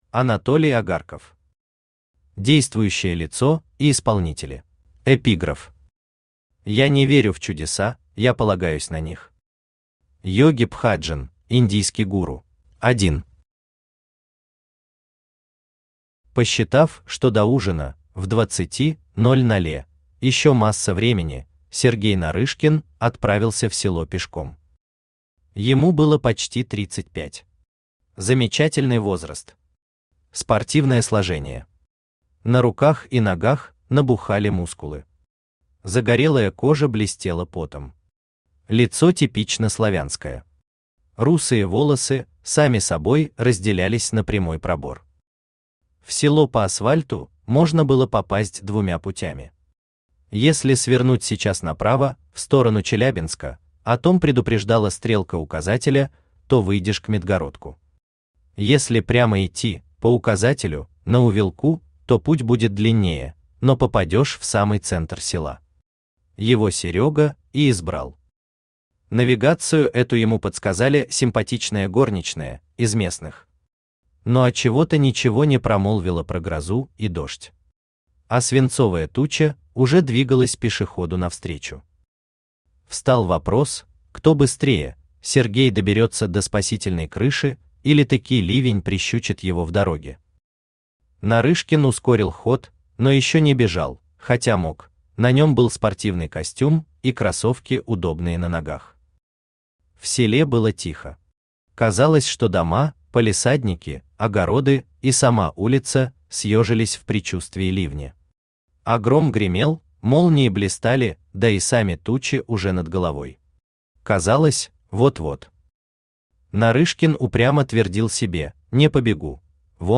Аудиокнига Действующее лицо и исполнители | Библиотека аудиокниг
Aудиокнига Действующее лицо и исполнители Автор Анатолий Агарков Читает аудиокнигу Авточтец ЛитРес.